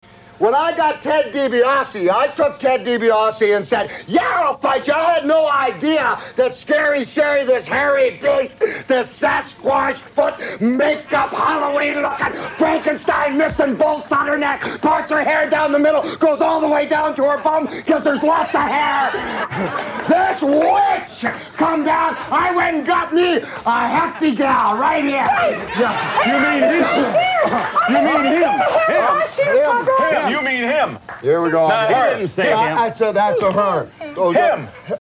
goes insane with a nonsensical never-ending rant, going off the deep end screaming about monsters and hairy beasts and sasquatches and a dozen other things.